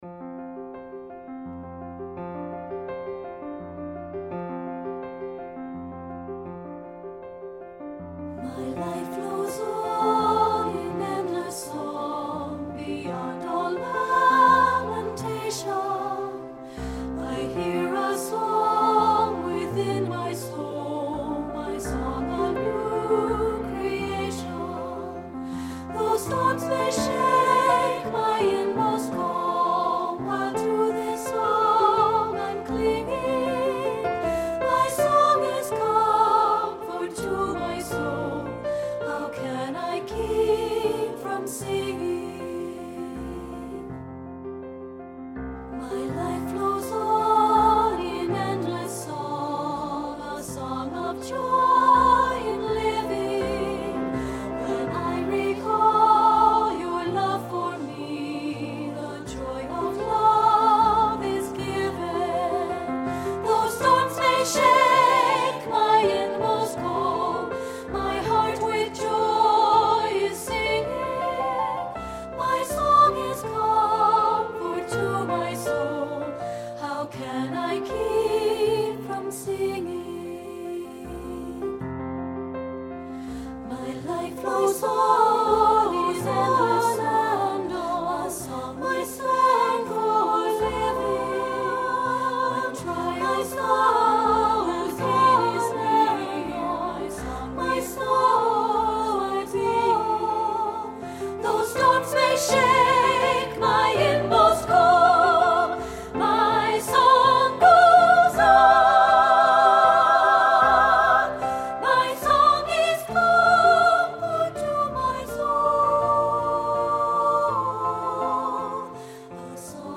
Composer: American Folk Hymn
Voicing: SATB